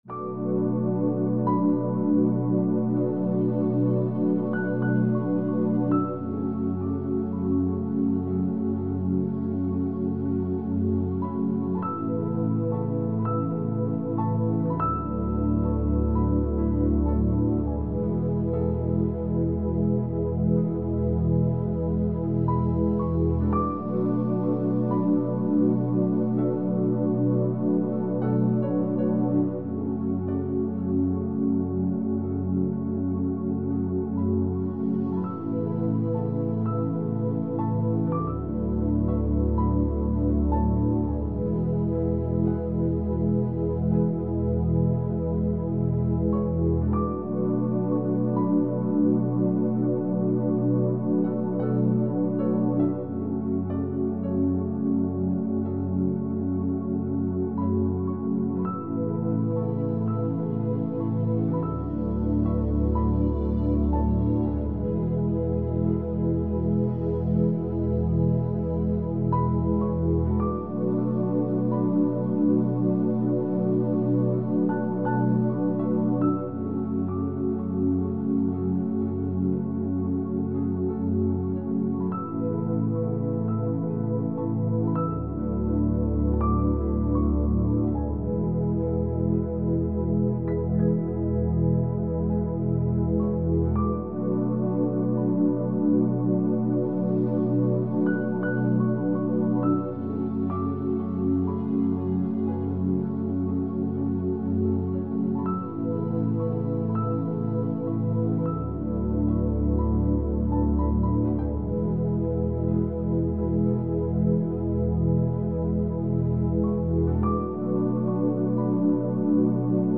Here comes the instrumental.